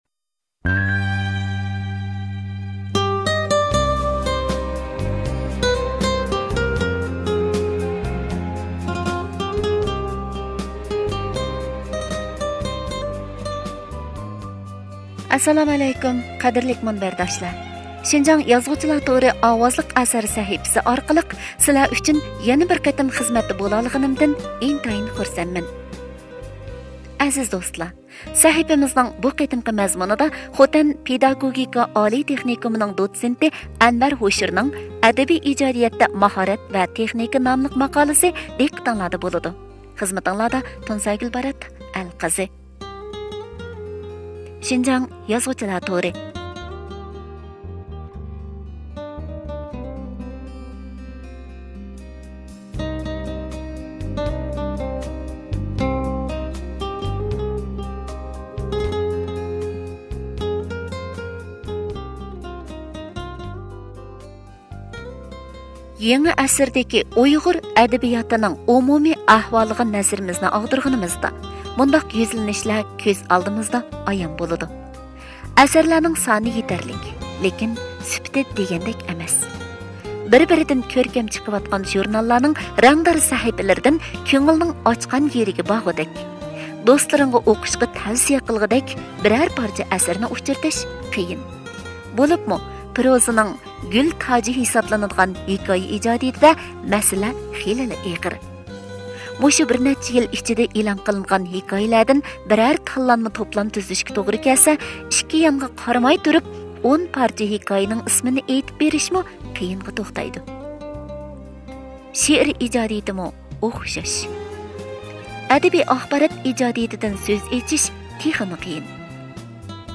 ئەدەبىي ئىجادىيەتتە ماھارەت ۋە تېخنىكا (ئاۋازلىق)